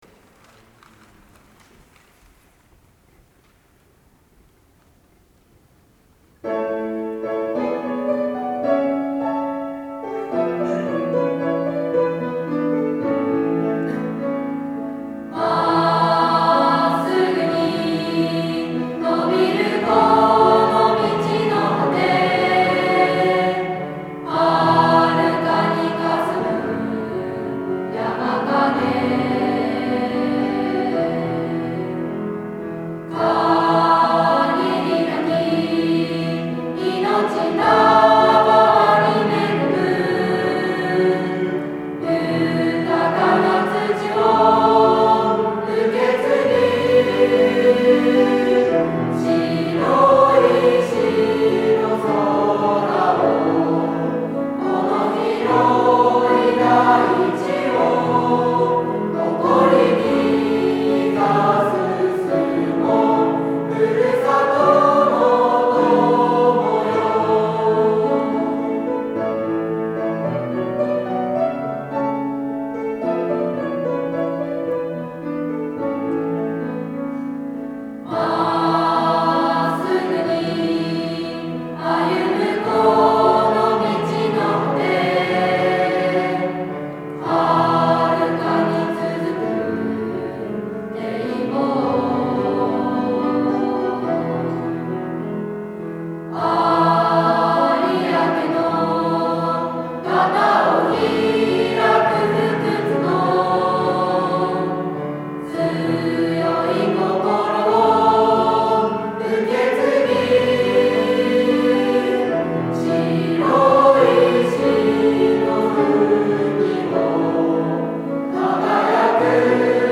8　　校歌斉唱
中学生が歌う校歌はこちらから→